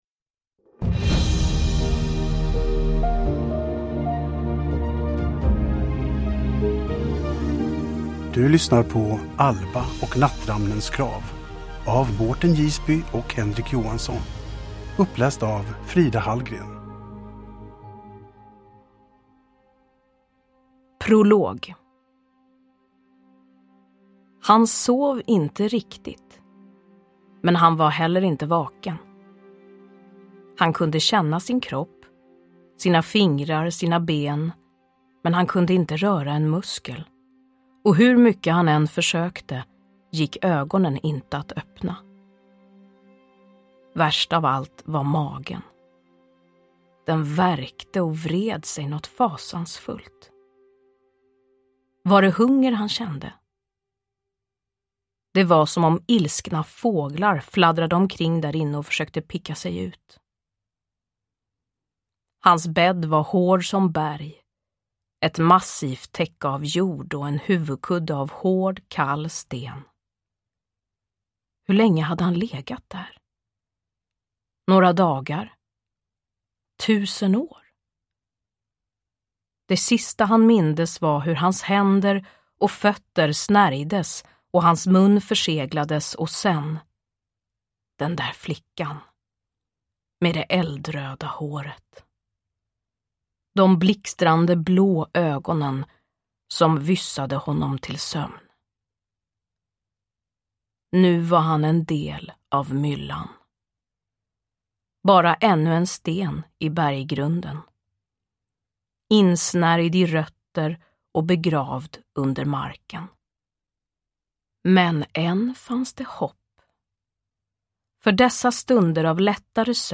Alba och Nattramnens grav – Ljudbok – Laddas ner
Uppläsare: Frida Hallgren